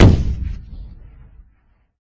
052-Cannon01.mp3